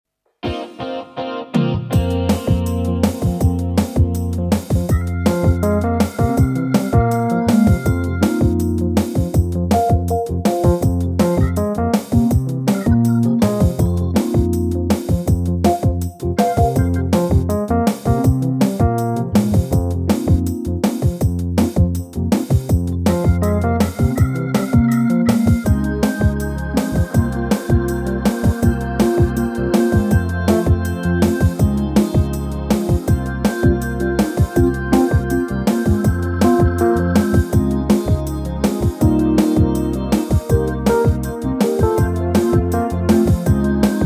Unique Backing Tracks
key - Fm (Ab) - vocal range - F to C
instantly catchy and danceable.